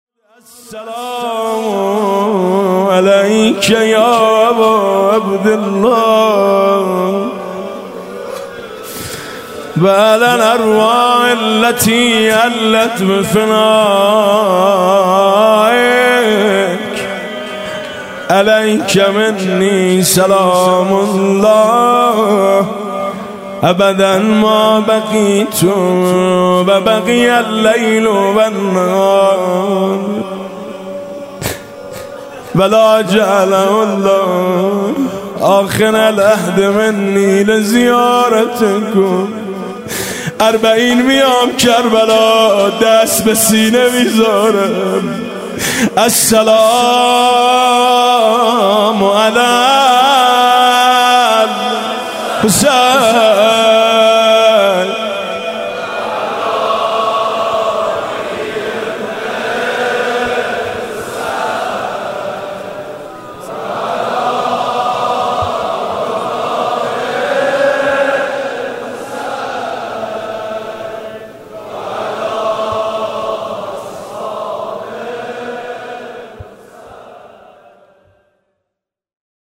• دانلود نوحه و مداحی
پخش آنلاین همین نوحه